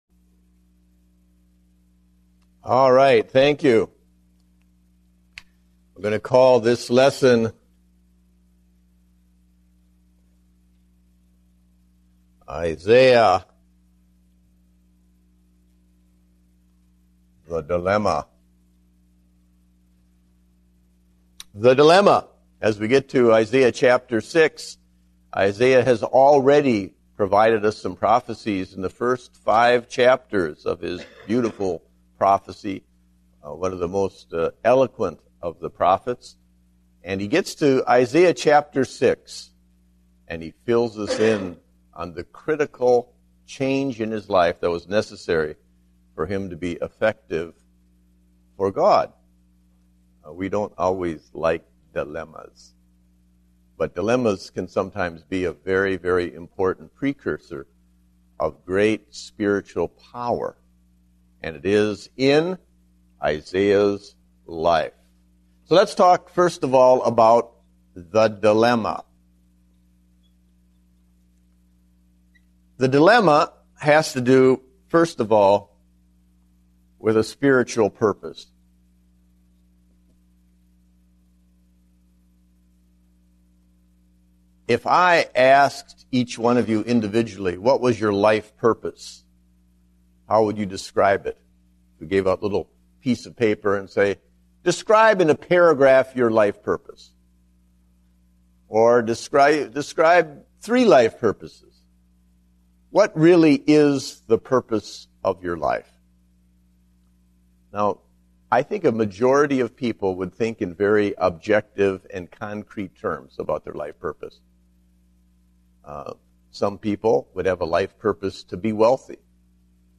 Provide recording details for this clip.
Date: January 17, 2010 (Adult Sunday School)